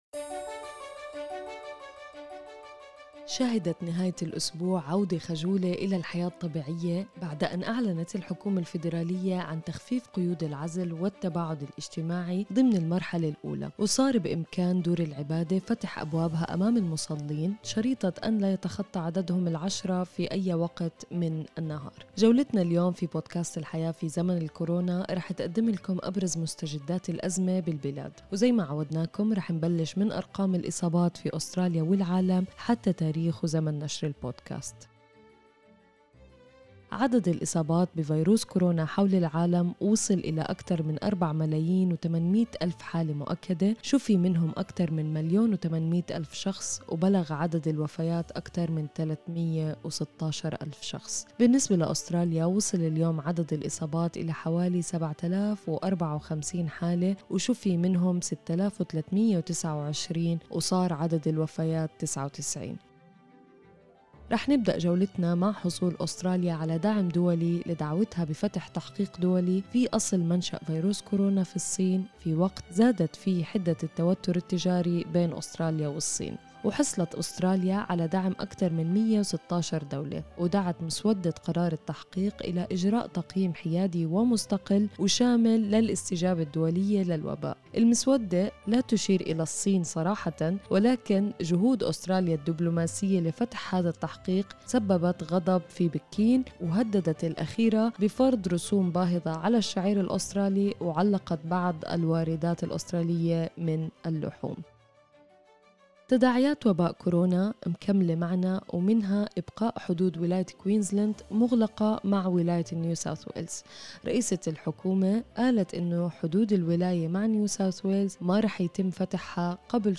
أخبار الكورونا اليوم 18/5/2020